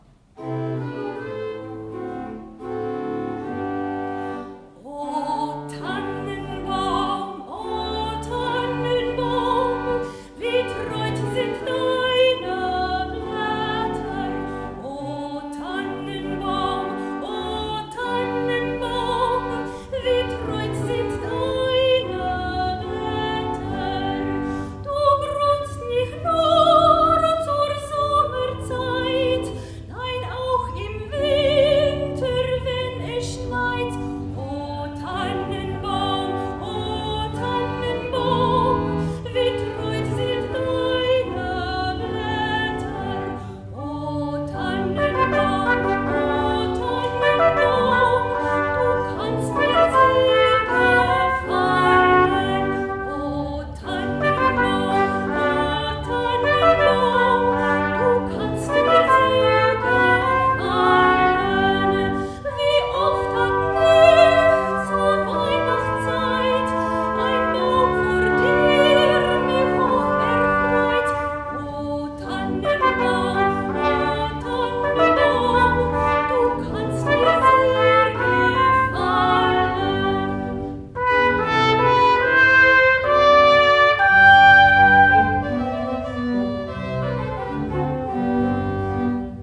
concert de Noël – église Ste Croix de Lorry
O Tannenbaum (trad. allemand)  –  Voix, Trompette et Orgue